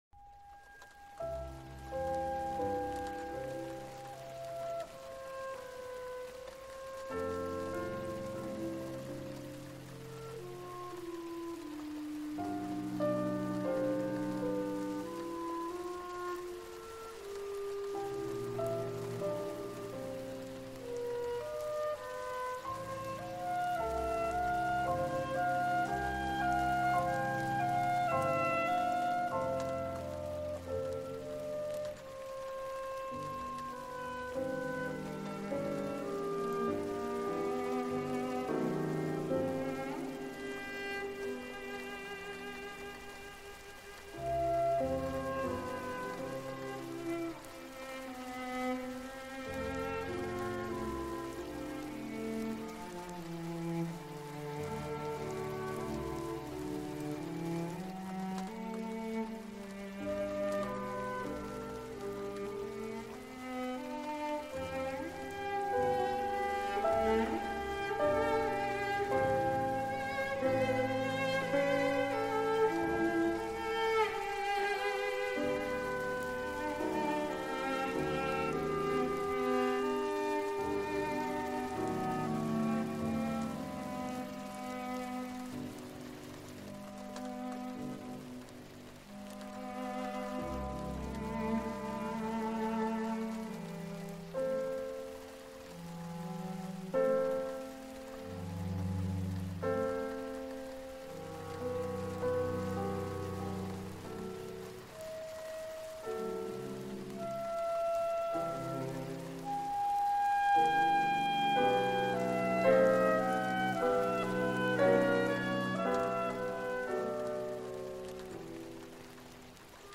Forêt Essentielle : Pluie et Feu